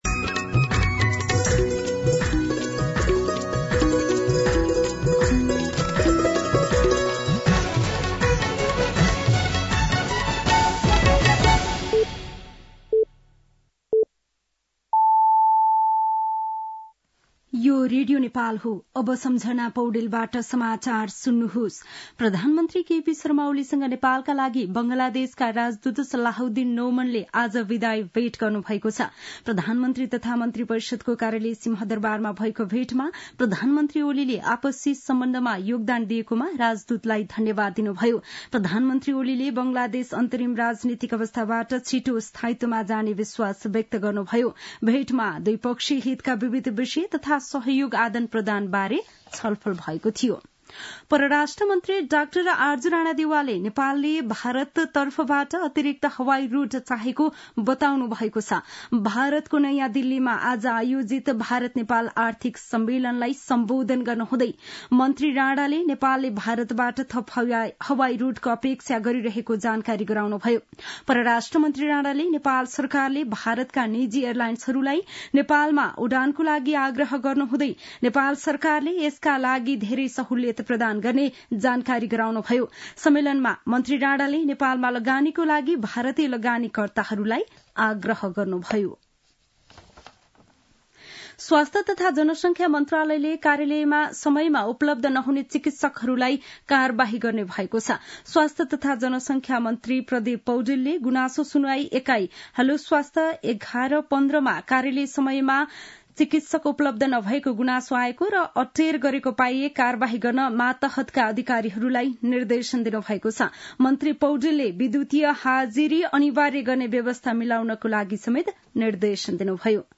दिउँसो ४ बजेको नेपाली समाचार : ६ पुष , २०८१
4-pm-nepali-news-3.mp3